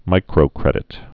(mīkrō-krĕdĭt)